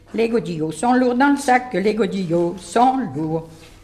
Mémoires et Patrimoines vivants - RaddO est une base de données d'archives iconographiques et sonores.
Couplets à danser
Pièce musicale inédite